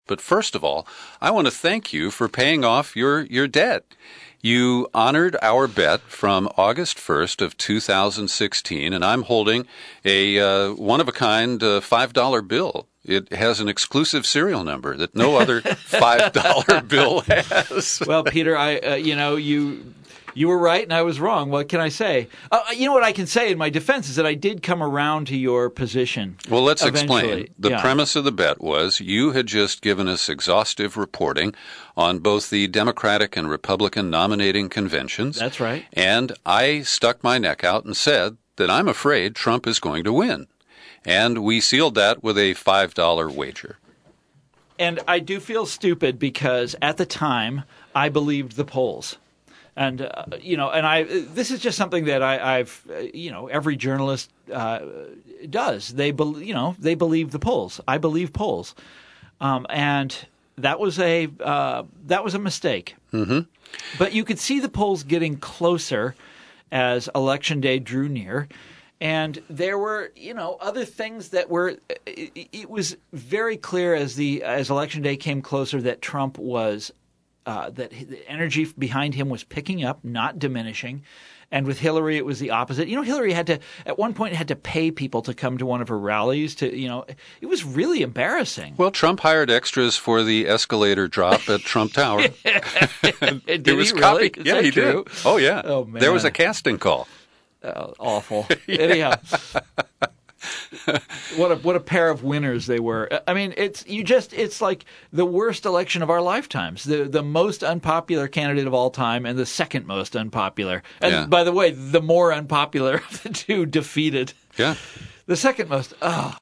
In-Depth Interview: Rich Conversation with Thomas Frank, Who Settles ’16 Bet and Flogs New Book